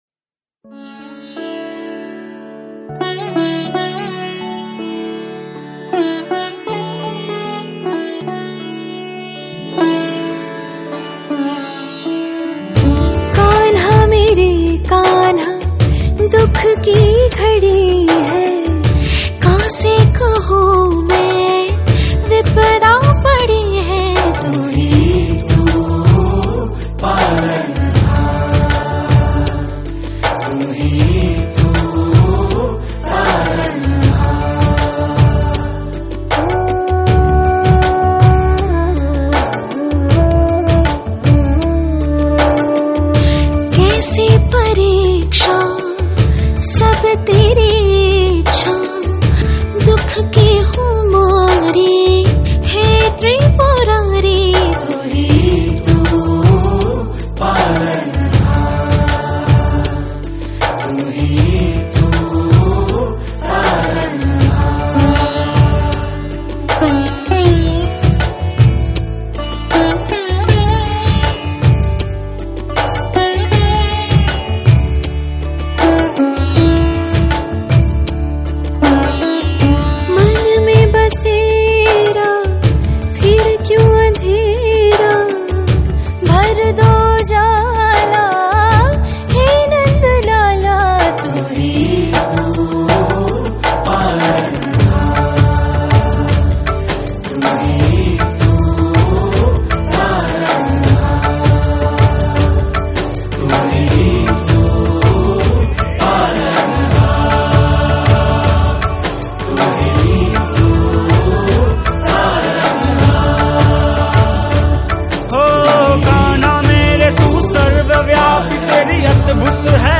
Bollywood Mp3 Music 2016